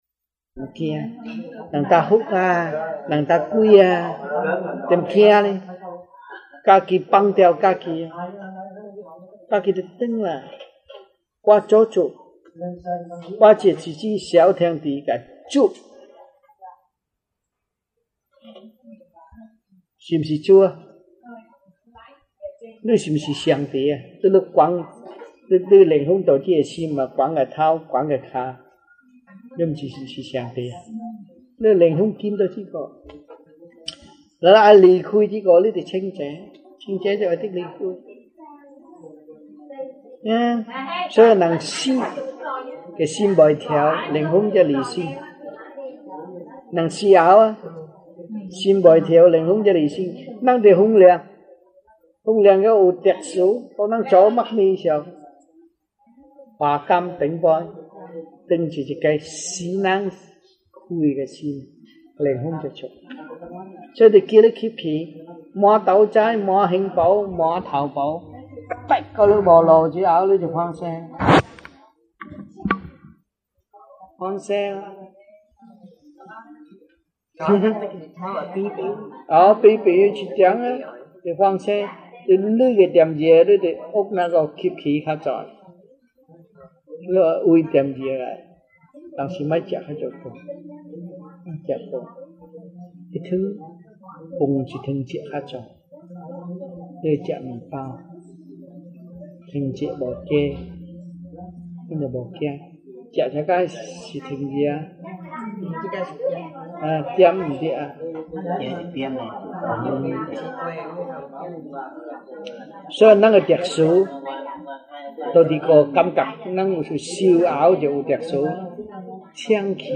Lectures-Chinese-1982 (中文講座)